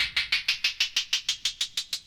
Xool Rise.wav